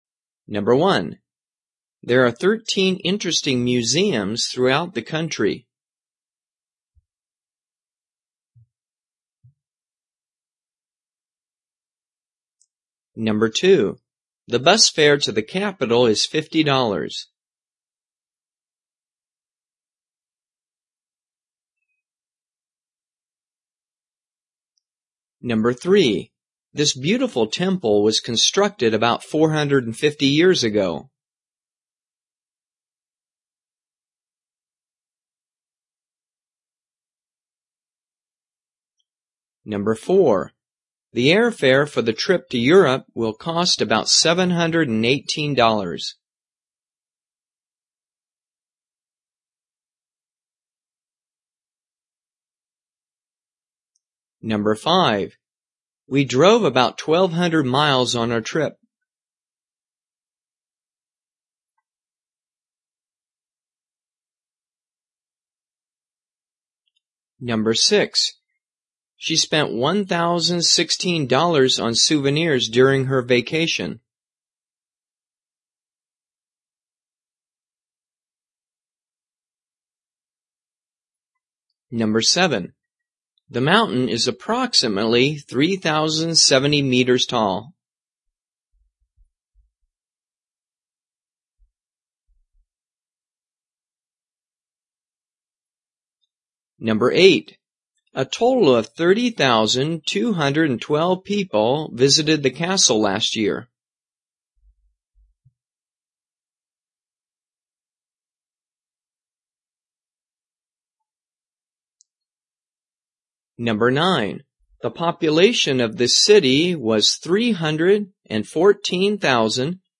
【听英文对话做选择】关于旅游的美好回忆 听力文件下载—在线英语听力室